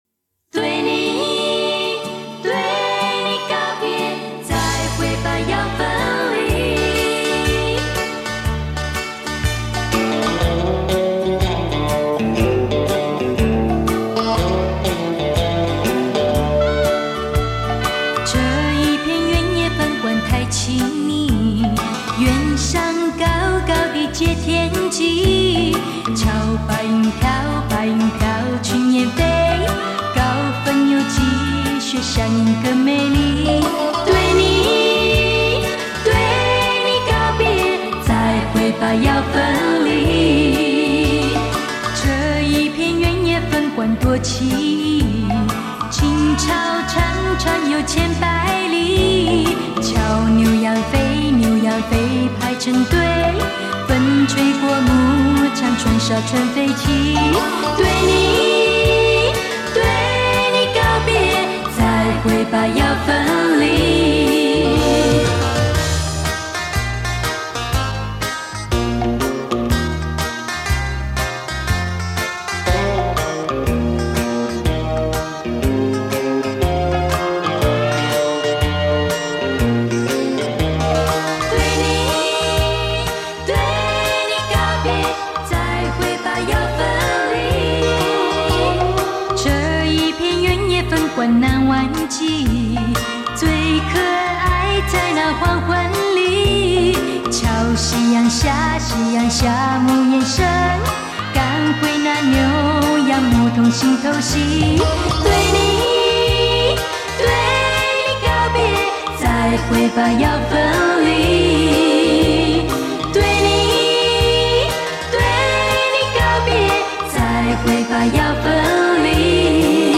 原版卡带-转-MP3
（低品质）